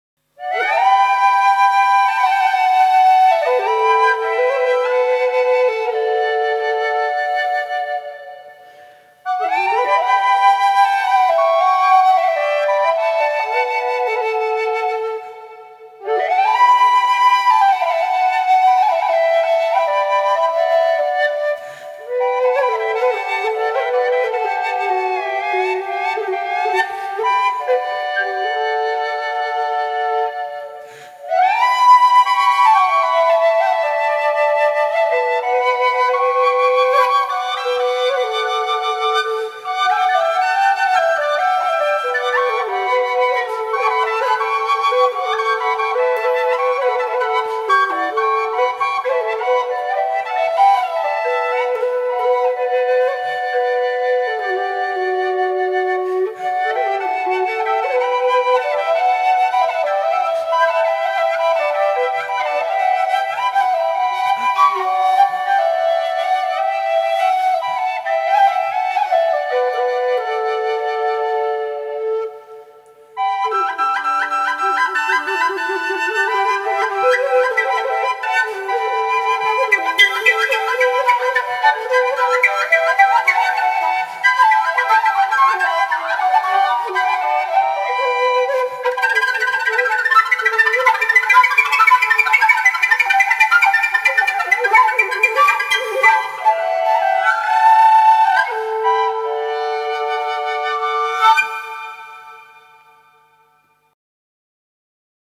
Gendung (Indonesian Drums)
Xylopt and drum kit